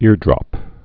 (îrdrŏp)